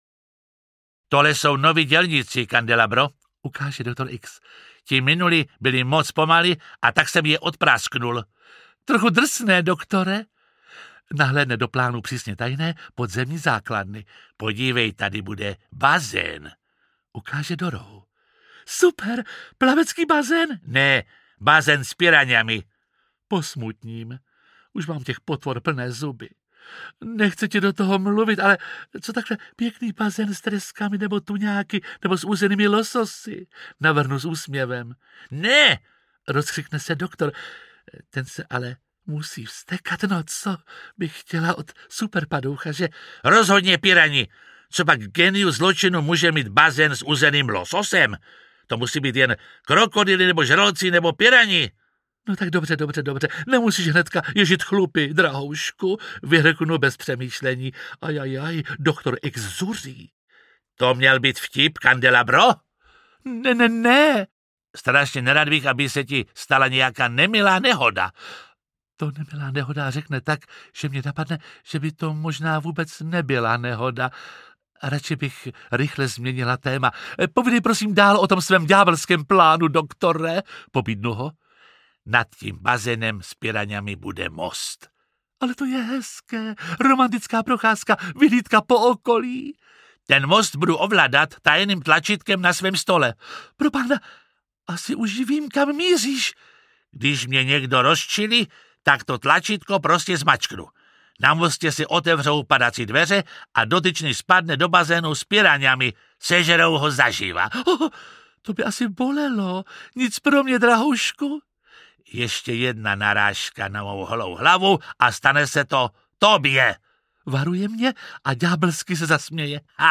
Nejhorší mazlíčci na světě audiokniha
Ukázka z knihy
• InterpretJiří Lábus